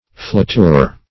flatour - definition of flatour - synonyms, pronunciation, spelling from Free Dictionary Search Result for " flatour" : The Collaborative International Dictionary of English v.0.48: Flatour \Fla*tour"\, n. [OF.]